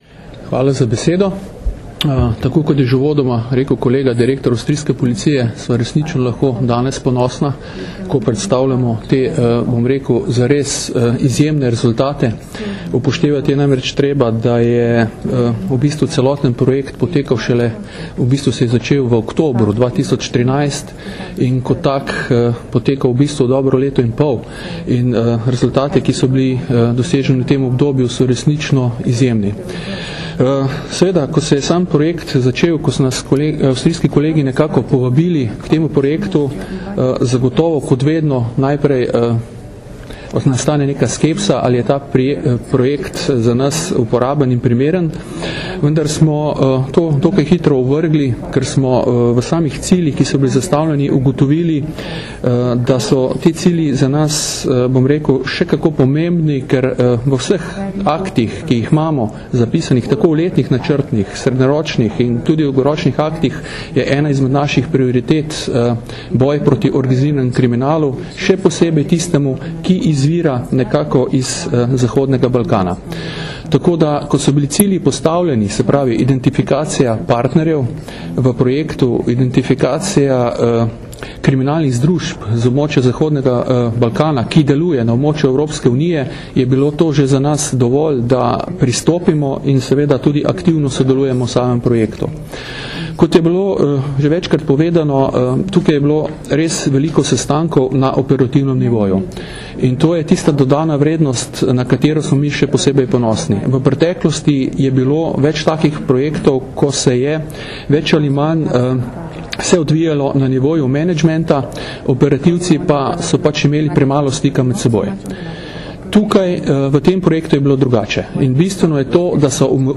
V Grand Hotelu Bernardin v Portorožu se je danes, 10. junija 2015, začela dvodnevna zaključna konferenca projekta WBOC - skupne preiskave z državami zahodnega Balkana v boju zoper organiziran kriminal in učinki na EU.
Zvočni posnetek govora mag. Branka Japlja, (mp3)
direktorja Uprave kriminalistične policije Generalne policijske uprave